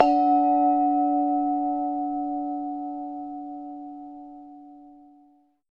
PRC BRGONG02.wav